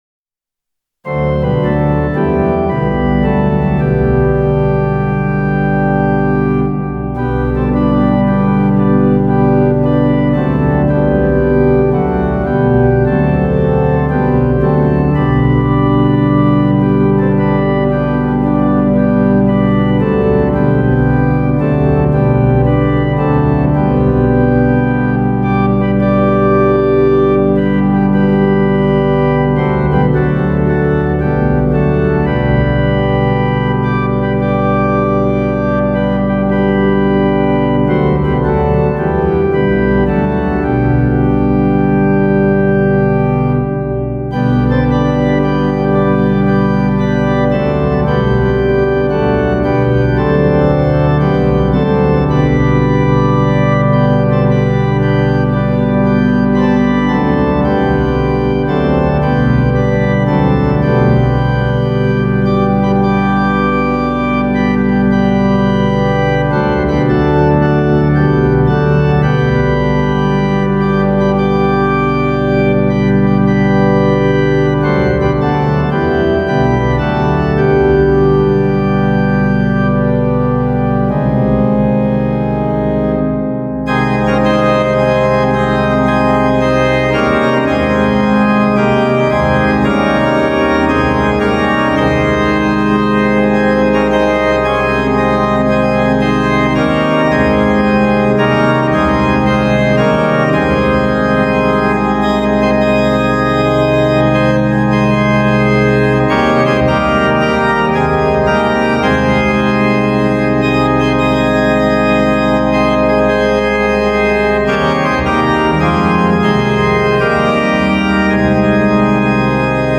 Instrument: Viscount Cantorum Duo Plus Recorded in October 2024
hymn organ pipeorgan